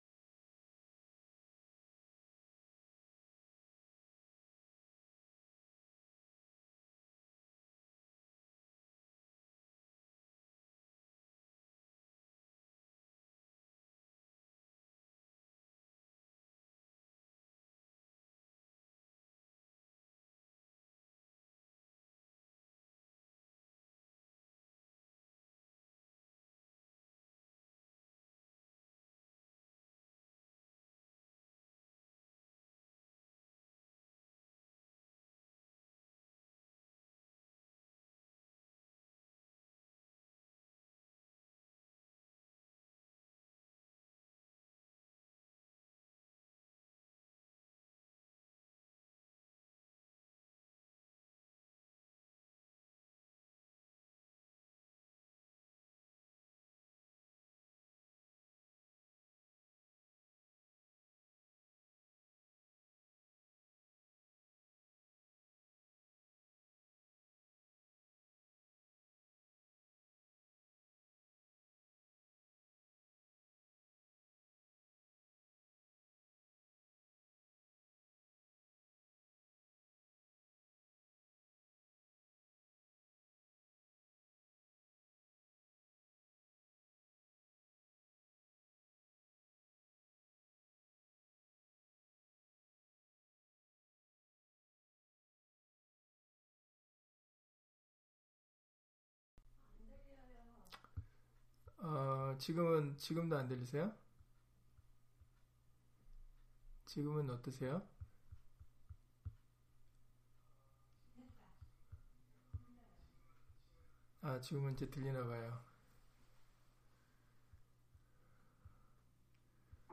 전도서 8장 11-13절 [의인에게 복이, 악인에게는 화가] - 주일/수요예배 설교 - 주 예수 그리스도 이름 예배당